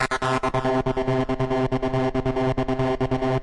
描述：用reFX Vanguard制作的旋律。声音二。
Tag: 标题 DJ 跟踪 melodics 旋律 精神恍惚 精神恍惚门 高潮 绕口令 舞蹈